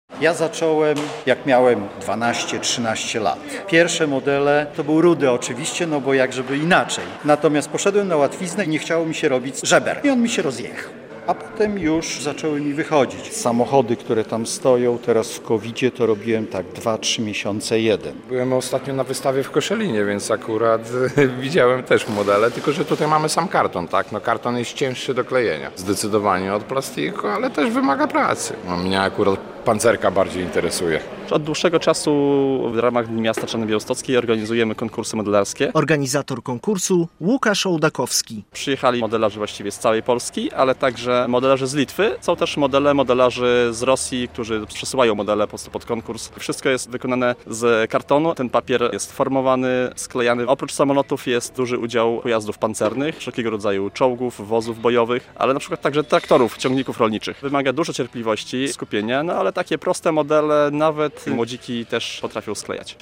Modele z papieru można oglądać w Czarnej Białostockiej - relacja